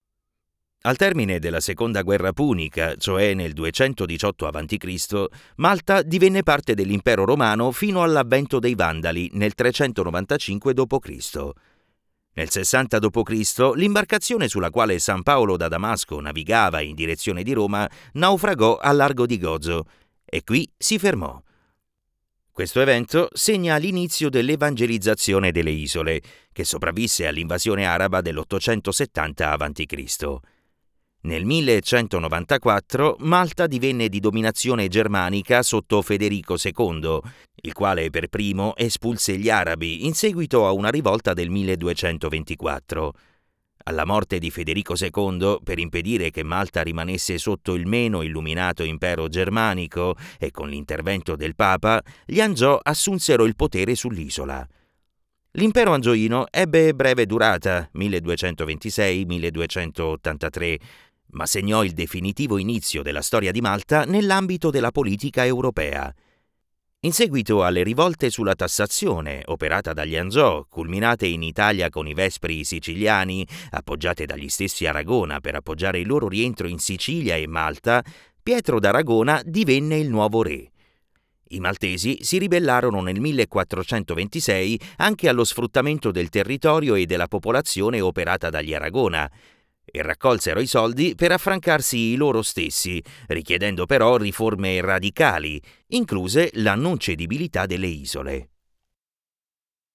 Male
Assured, Authoritative, Character, Corporate, Friendly, Warm, Versatile
Baritone vocal modulation, moderate or "extreme" characterization, at the request of the client, expressions and use of "dialectisms" or accents of various regions and nationalities, make sure that my voice is suitable in any context.
Microphone: RODE NT1 5th Gen - Neumann TLM103